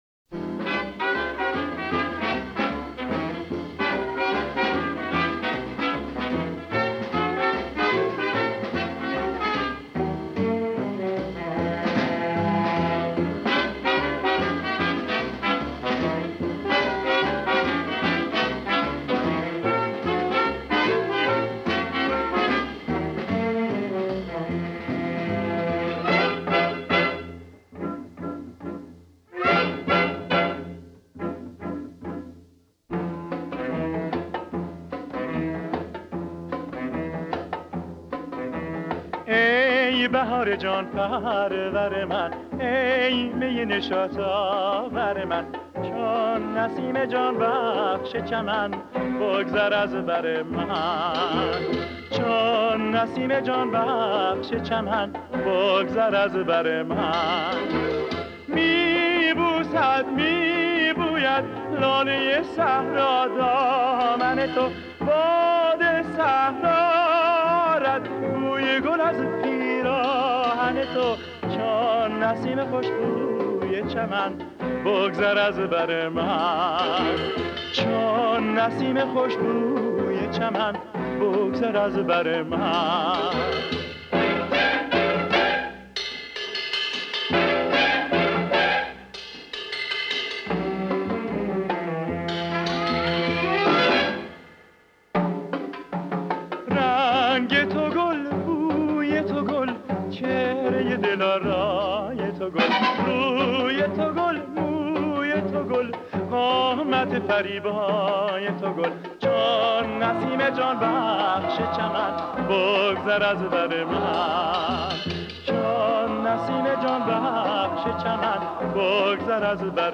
در مایه اصفهان